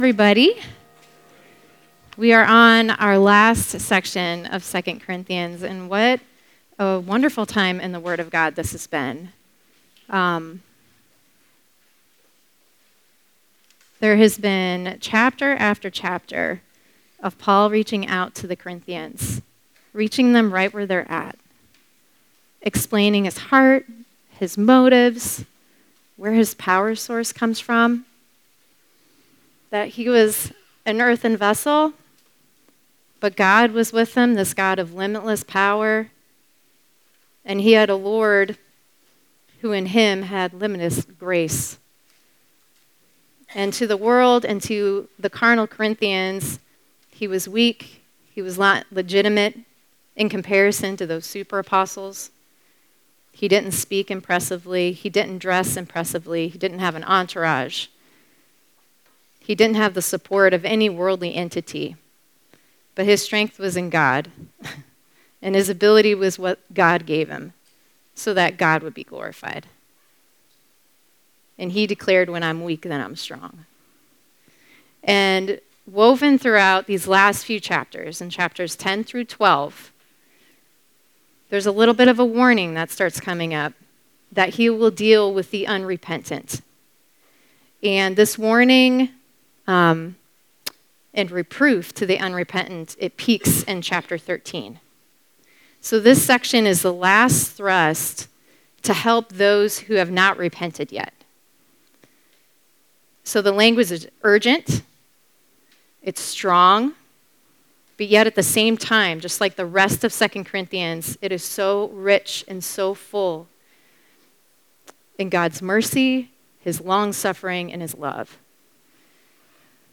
Treasure in Jars of Clay (Family Camp 2022